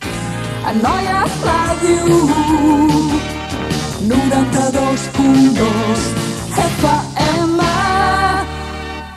Indicatiu de l'emissora
Indicatiu cantat